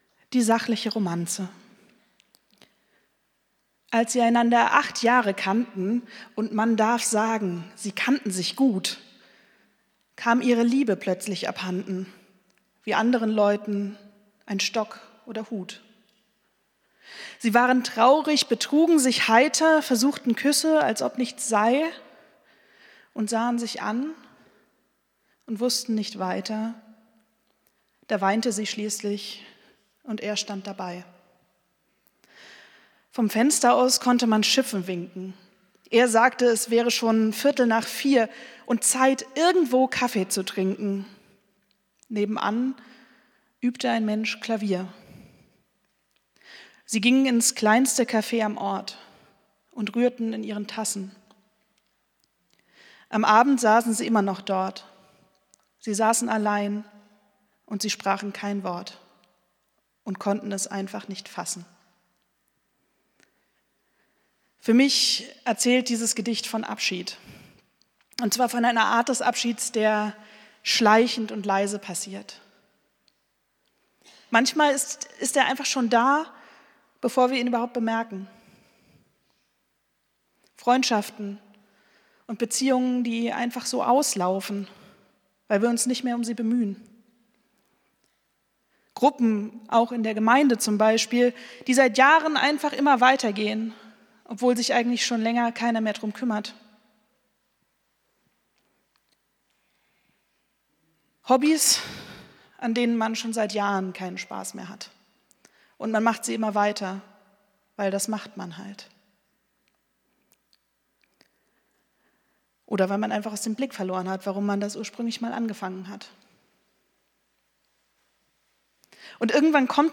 Predigt vom 02.11.2025